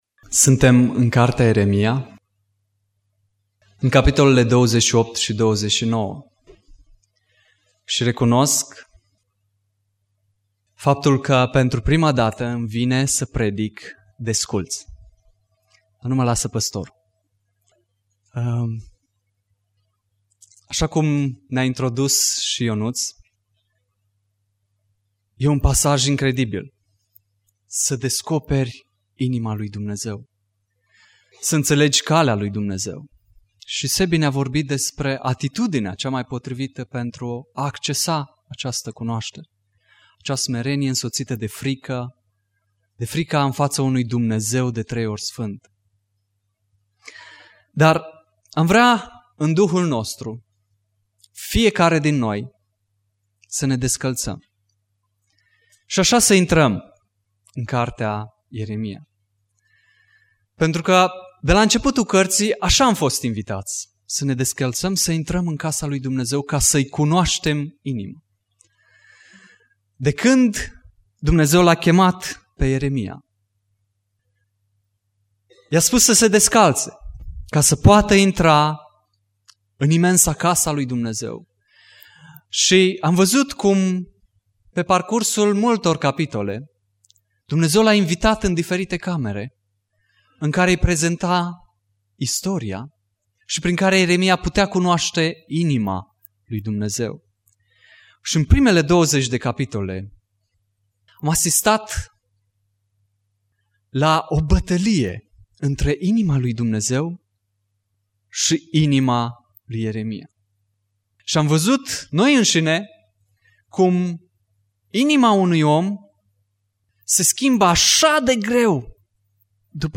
Predica Exegeza- Ieremia 28-29